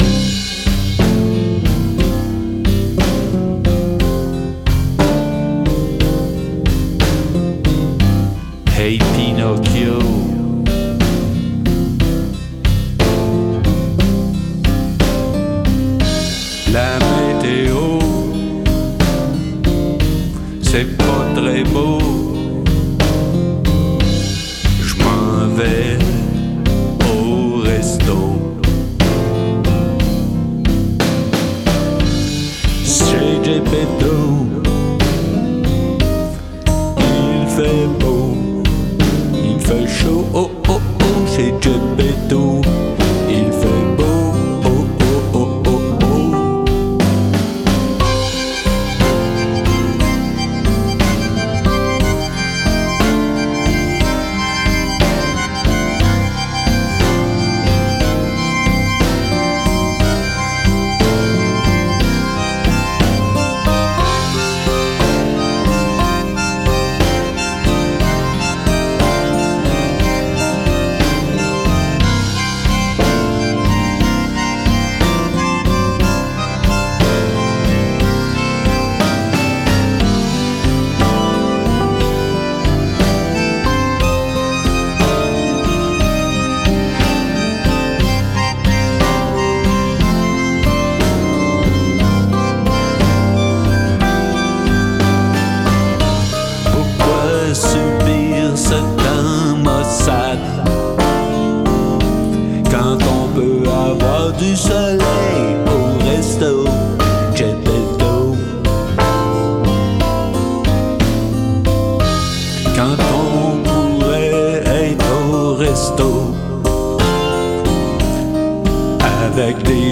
C’est comme ça qu’il a composé ce blues recherché.